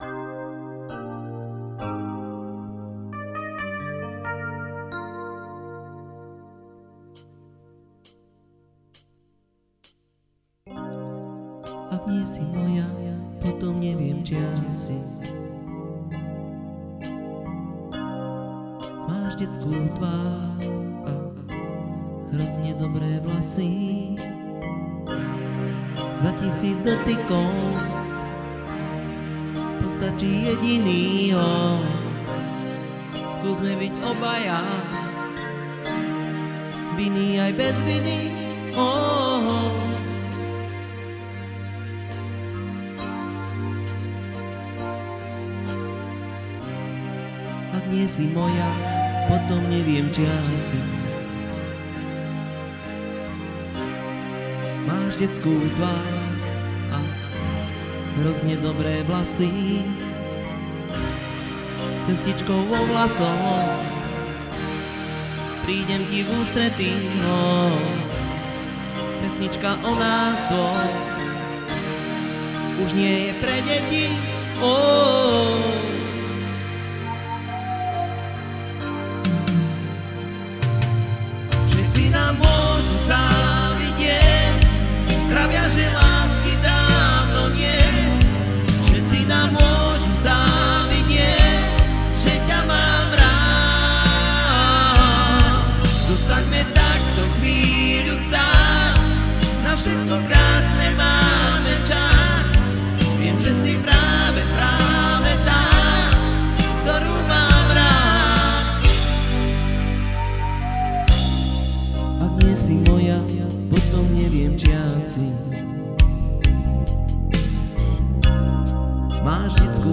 spieva